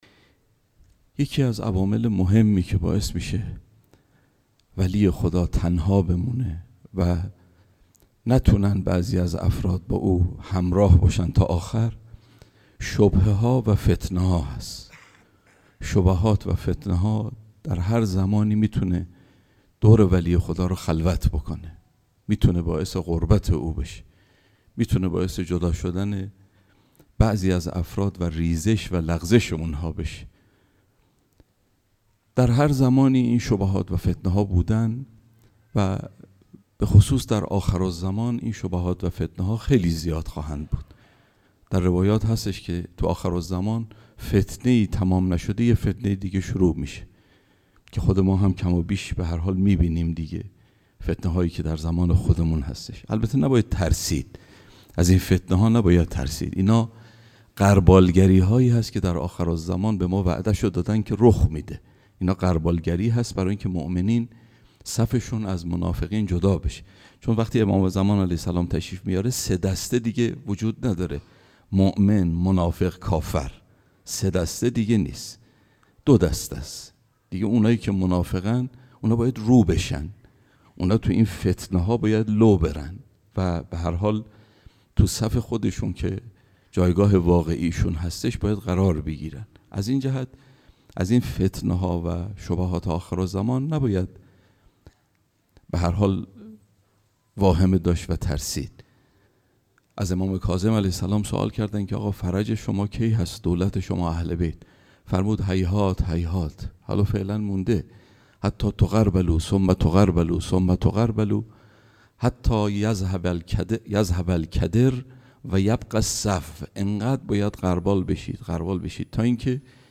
شب گذشته در مسجد الهادی